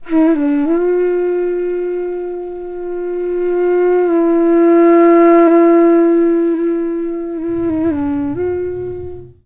FLUTE-A06 -L.wav